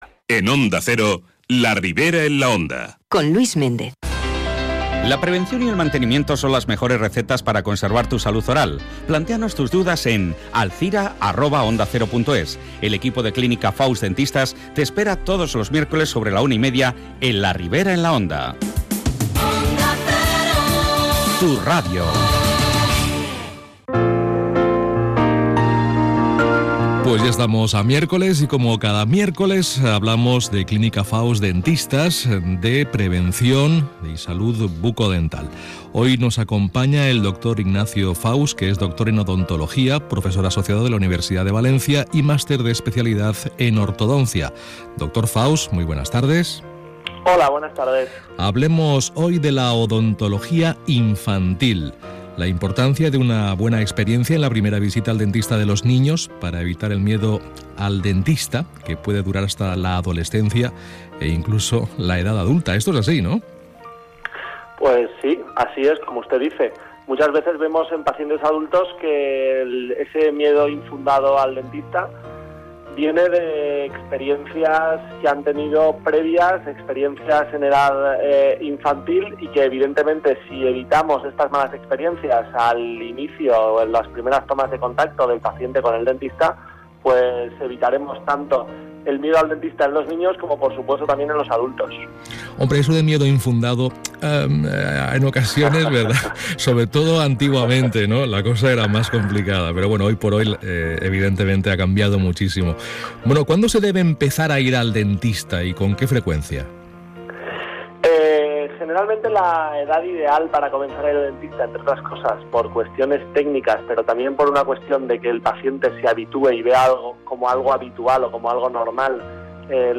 Entrevista en Onda Cero Alzira